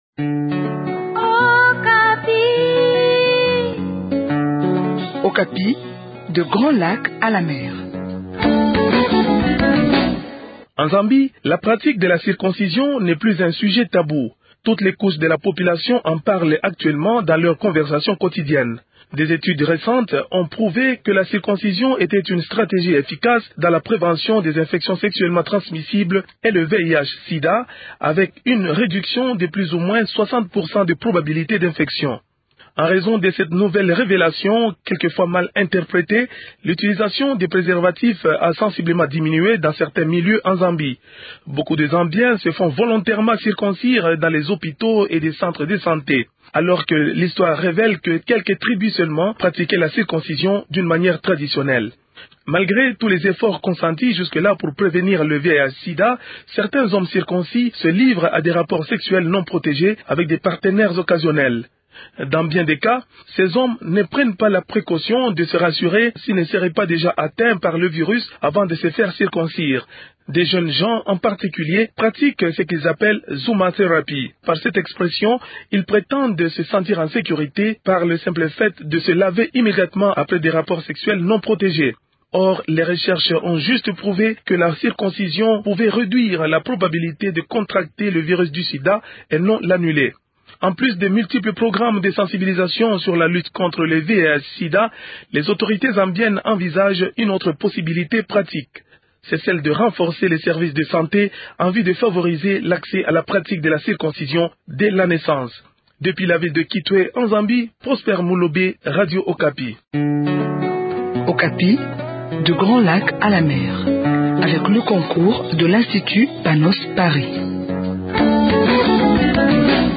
Les détails avec notre correspondant sur place